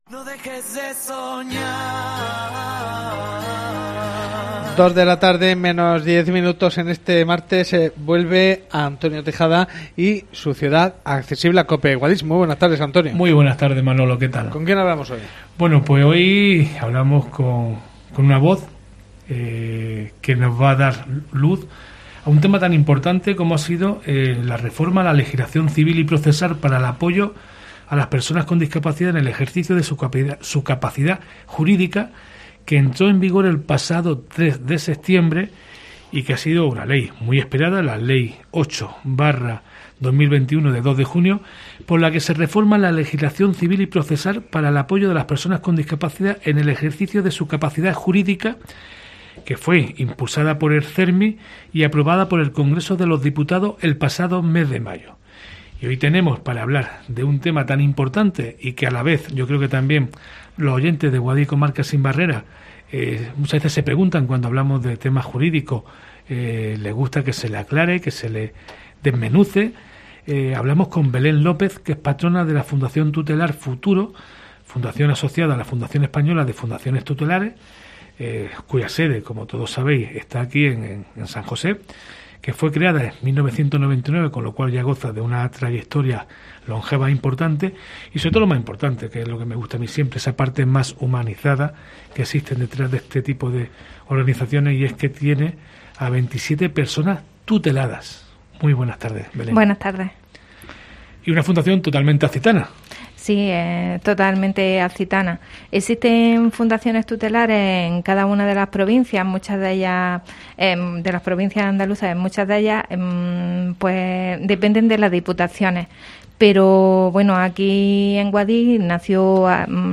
Entrevista Fundación Tutelar Futuro